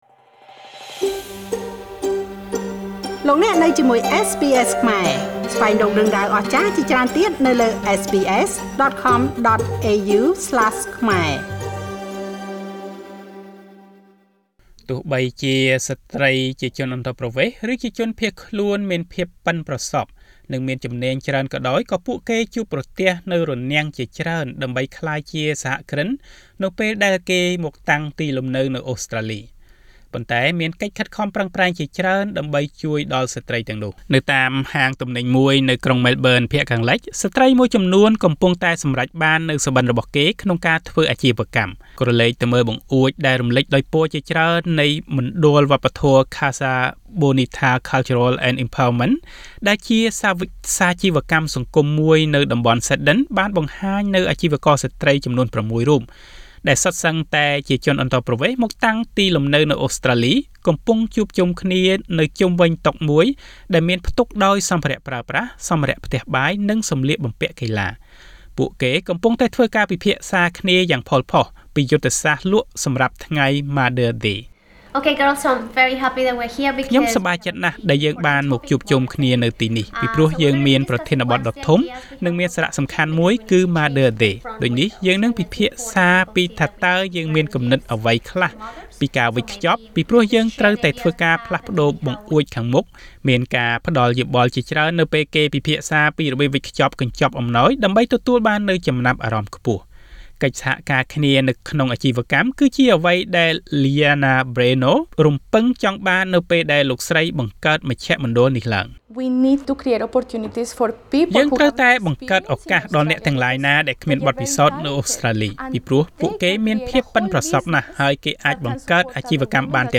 ការមកតាំងលំនៅនៅប្រទេសថ្មីតែងតែមានការប្រឈមផ្សេងៗពីព្រោះអ្វីៗអាចមានលក្ខណៈខុសប្លែកពីប្រទេសកំណើត។ ការប្រឈមនេះទៀតសោតក៏មានកាន់តែច្រើនចំពោះស្ត្រីដែលមានបំណងបើកអាជិវកម្មដោយខ្លួនឯងផងដែរ។ តទៅនេះសូមស្តាប់របាយការណ៍ស្តីពីការប្រឈមរបស់ស្ត្រីអន្តោប្រវេសន៍ក្នុងការប្រកបអាជីវកម្មនៅអូស្ត្រាលីដូចតទៅ ៖